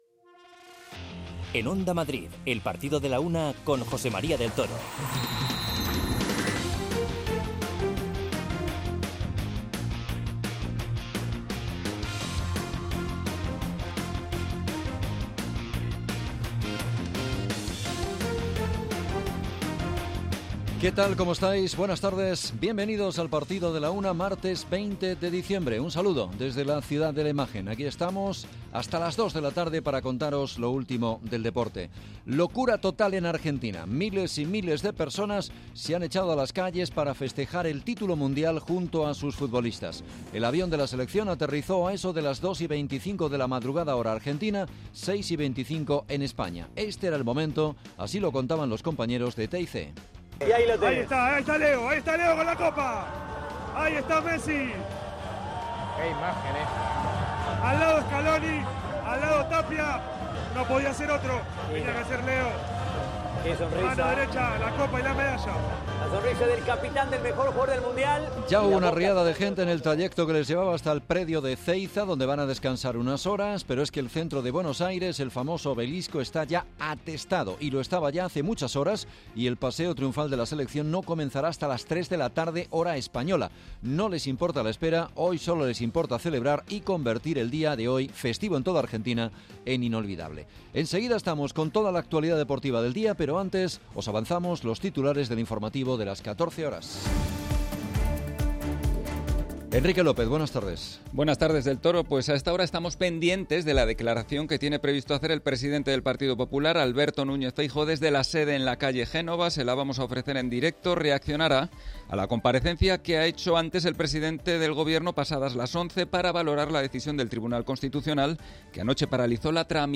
Conectamos con uno de los hinchas presentes cerca del Obelisco que nos cuenta el riesgo que entraña la celebración con tantísima gente.